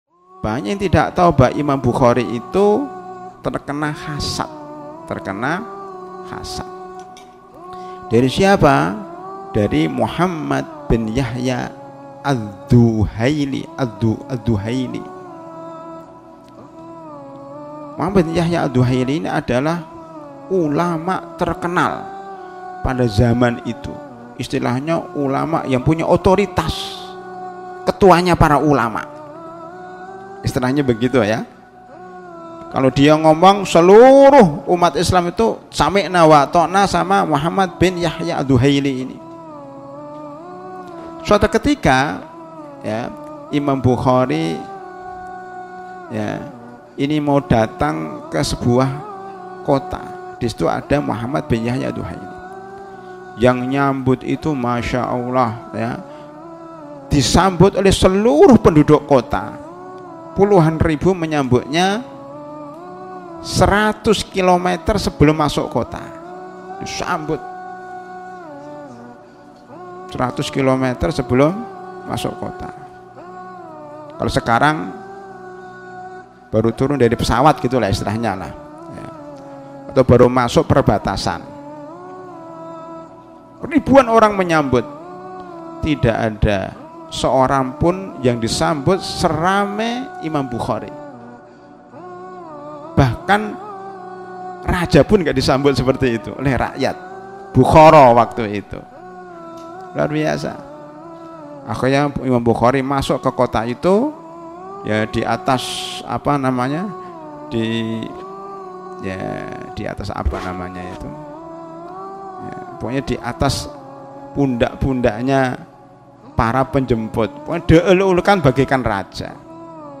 Terakhir diperbaru: Jum'at, 24 April 2026 14:01, Kajian Audio, Kajian Tematik